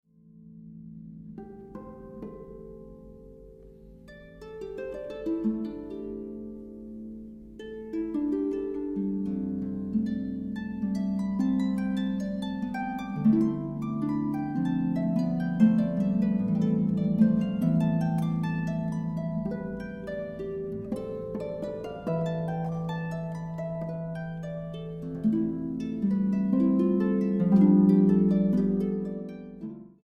arpa.